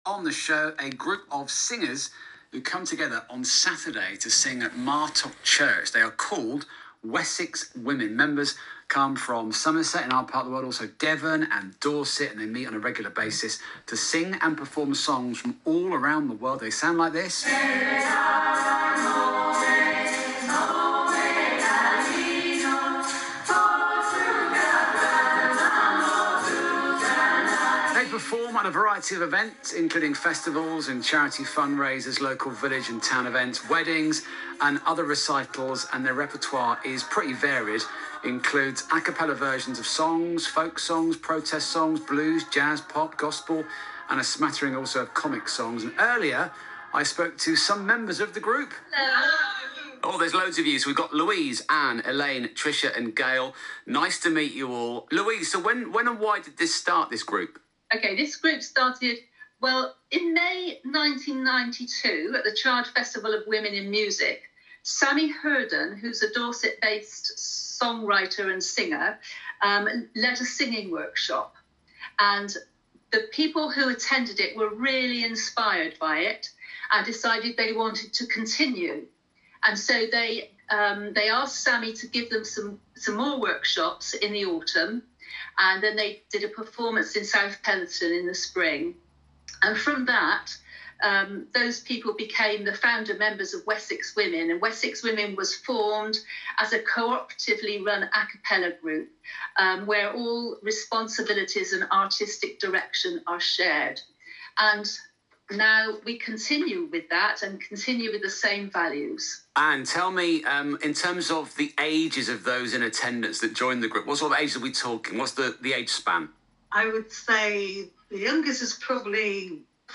Interview with Radio Bristol
Interview-WW-Radio-Bristol.mp3